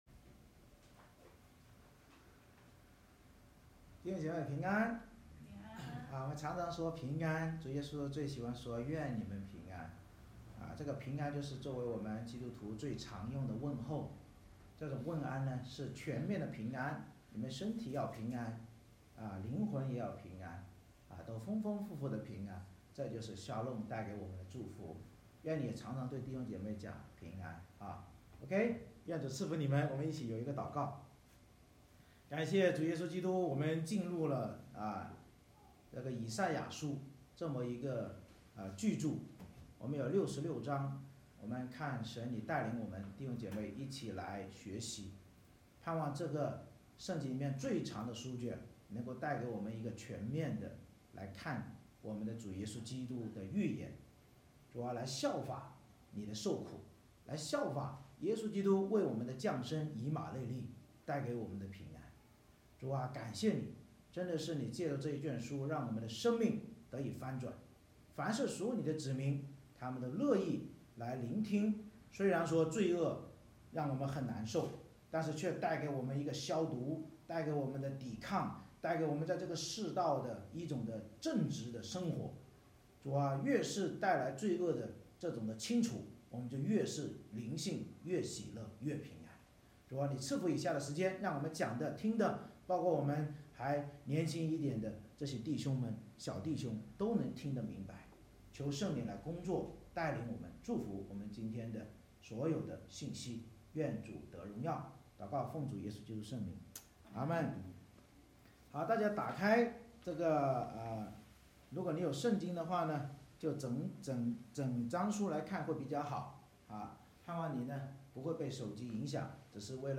以赛亚书2:1-22 Service Type: 主日崇拜 以赛亚得到犹大和耶路撒冷的默示，预告末后主基督必再来审判万民，劝勉我们不要依靠世人和偶像，唯有归向基督及其教会才有得救真道。